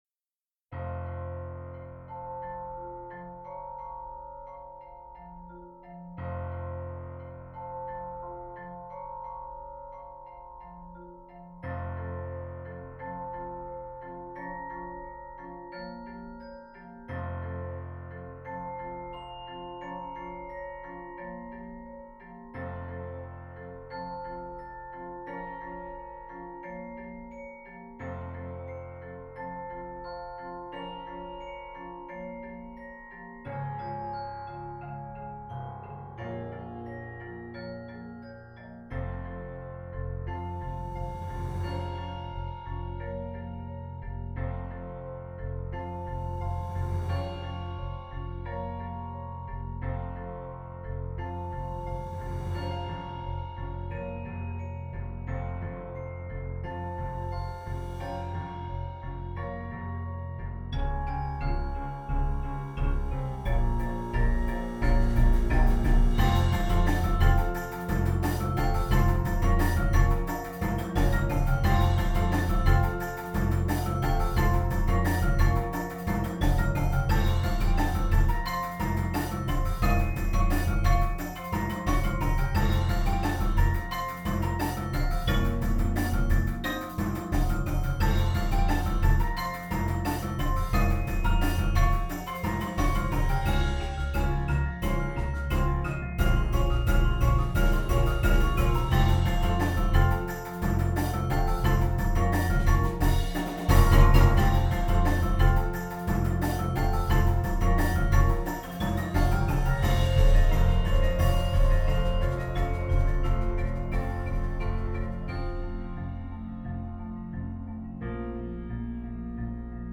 Voicing: Marimba Quartet